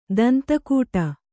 ♪ danta kūṭa